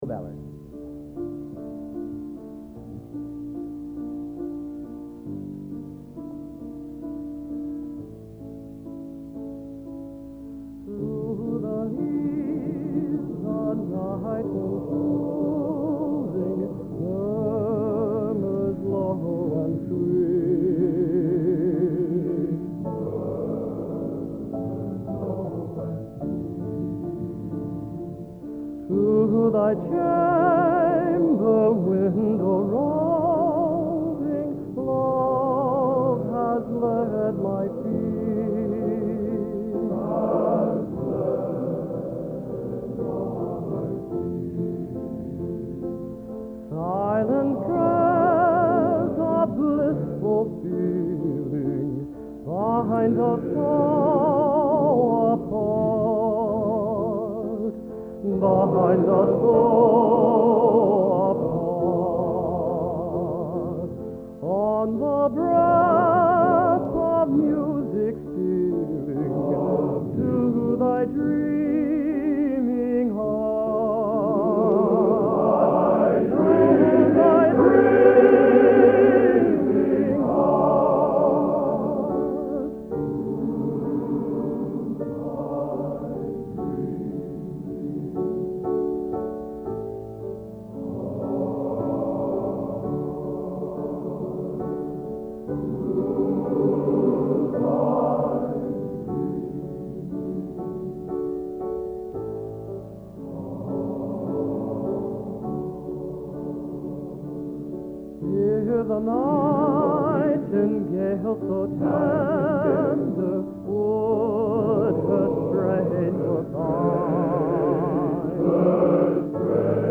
Location: West Lafayette, Indiana
Genre: | Type: End of Season |Featuring Hall of Famer